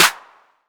Clap (12).wav